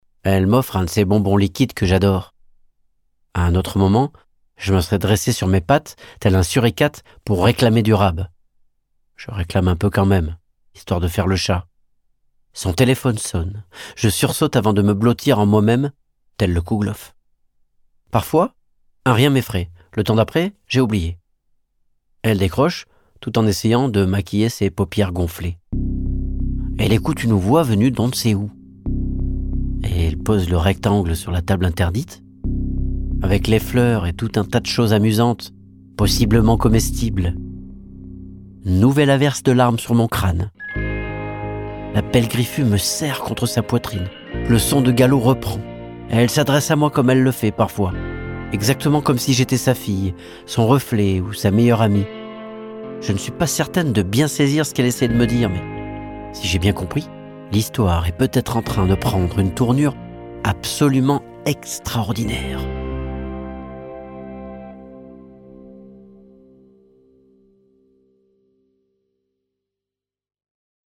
Livres Audio
« L’homme qui écoutait battre le coeur des chats » de Mathias Malzieu, lu par l’auteur